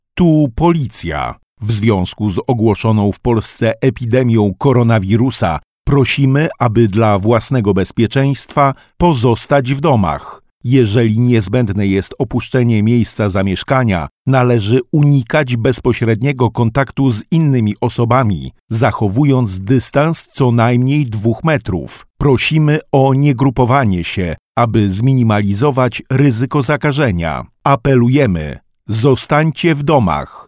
Od trzech do czterech razy na dobę, na terenie miasta Mielca będzie można usłyszeć komunikat nadawany przez Komendę Powiatową Policji w Mielcu oraz Powiatowy System Alarmowania. W treści komunikatu znajdzie się przede wszystkim prośba o niegrupowanie się osób, w celu zminimalizowania ryzyka rozprzestrzeniania się koronawirusa.
Komunikaty głosowe nadawane będę wyłącznie w godzinach od 8.00 do 20.00. A już dziś wiadomo, że w Mielcu objętych kwarantanną domową jest ponad 460 osób.
2-komunikat-policja-mc.mp3